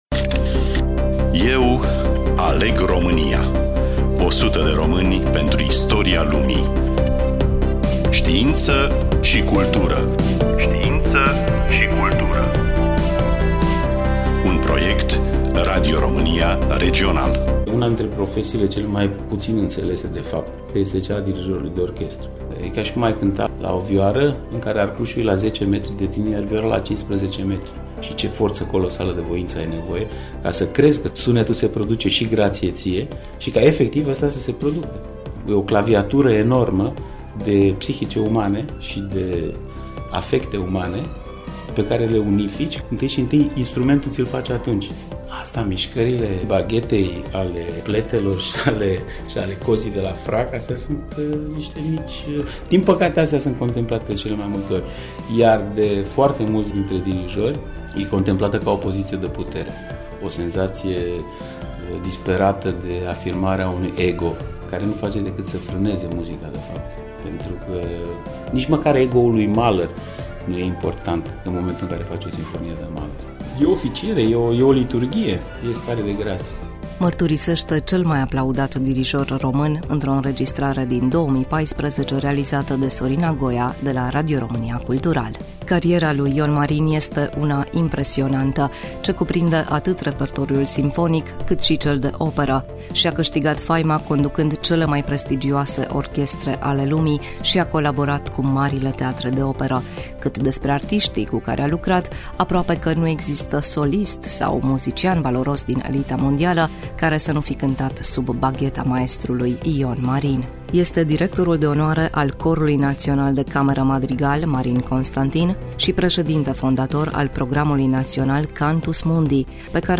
Studioul: Radio România Cluj